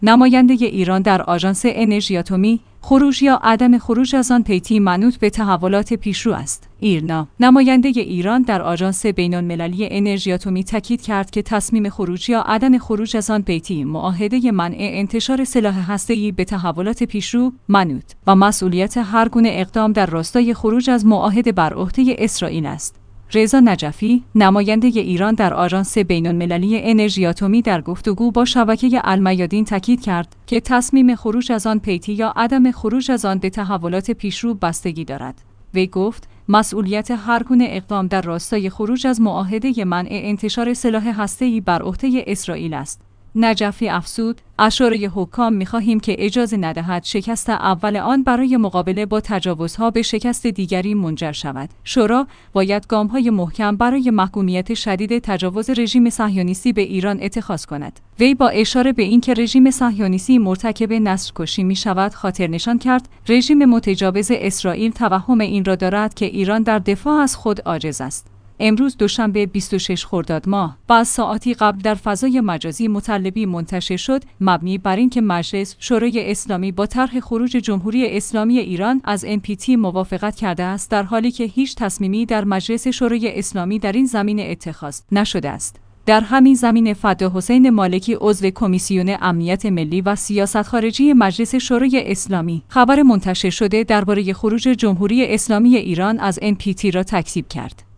رضا نجفی، نماینده ایران در آژانس بین المللی انرژی اتمی در گفت وگو با شبکه ا